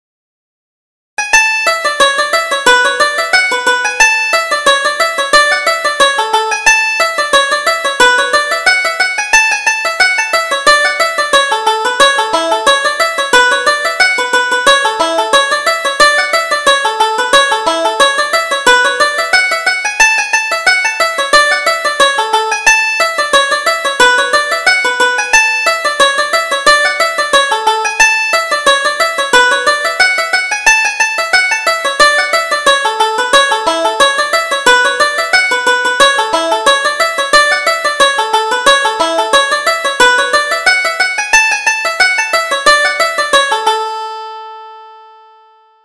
Reel: The Linen Cap